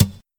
hit_2.ogg